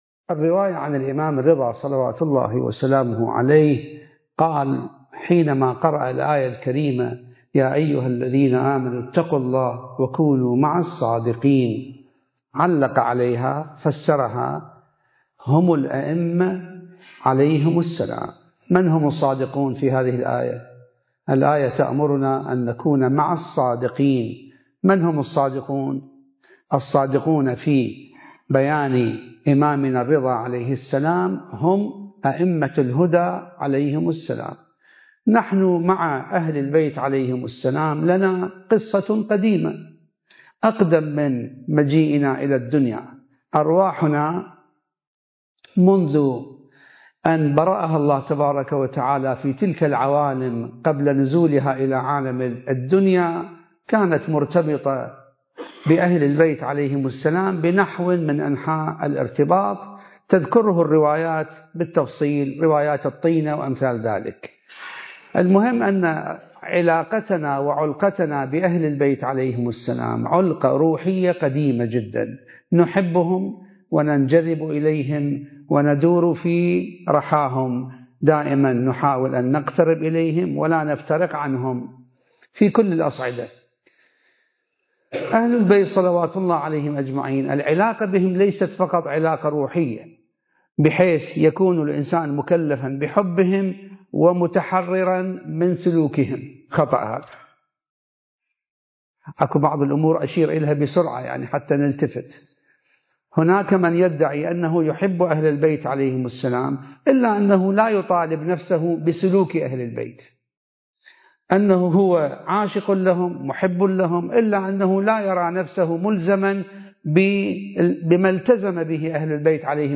المكان: الحسينية المهدية - مركز الدراسات التخصصية في الإمام المهدي (عجّل الله فرجه) - النجف الأشرف ضمن فعاليات (الندوات المهدوية)